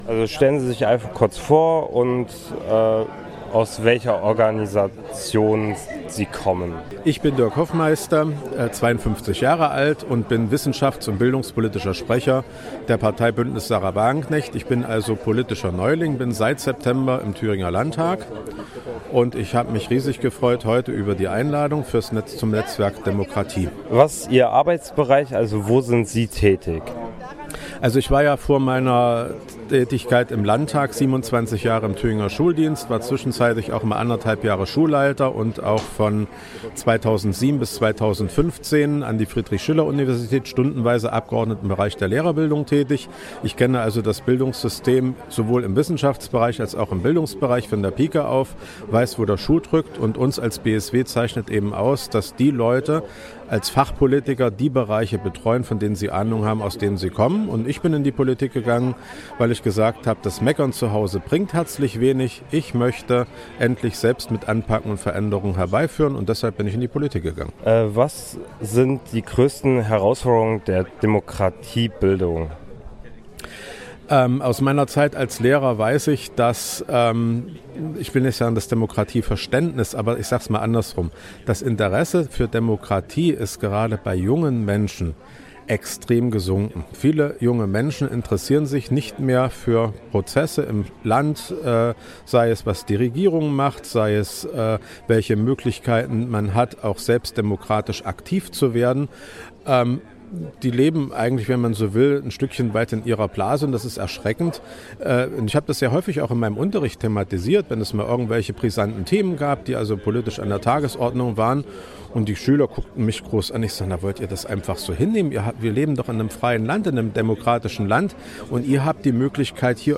Sommerfest des Netzwerks Demokratiebildung Thüringen | Stimmen zum Nachhören
Radio F.R.E.I. und das Jugendforum Erfurt waren vor Ort und haben mit verschiedenen Beteiligten gesprochen.